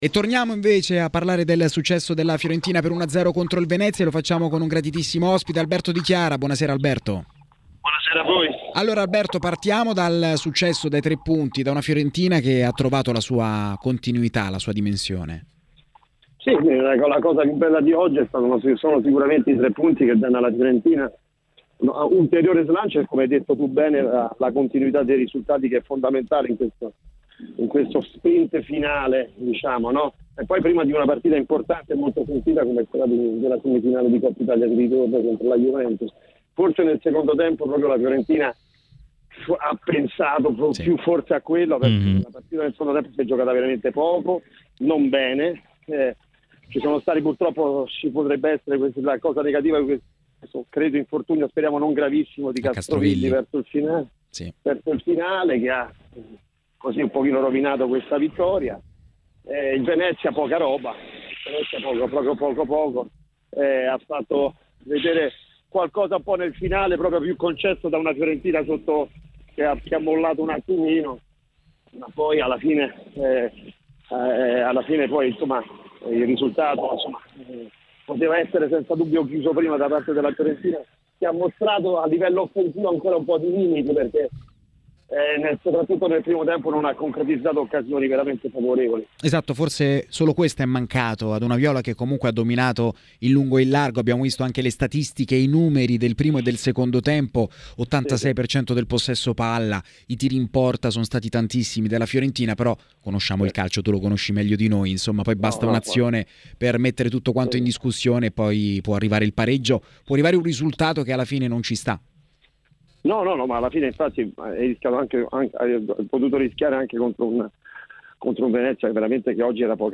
Ai microfoni di TMW Radio è intervenuto l’ex calciatore Alberto Di Chiara: “La Fiorentina?